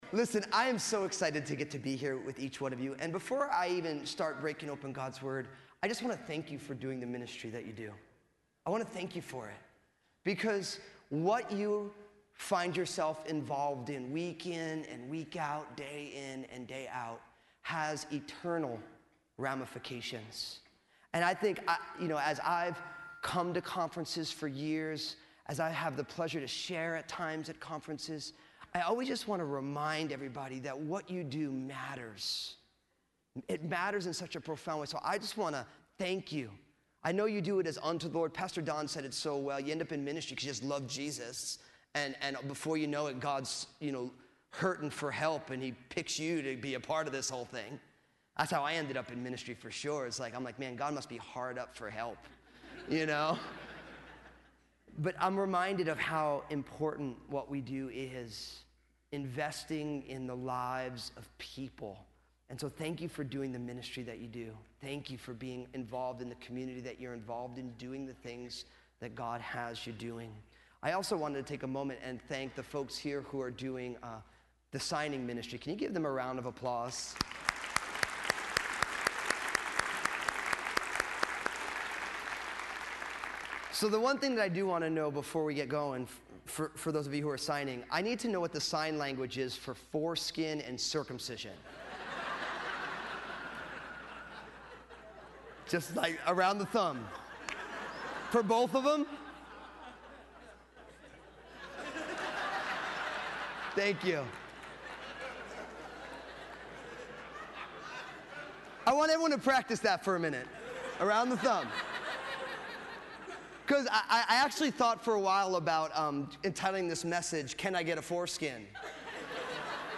2015 SW Pastors and Leaders Conference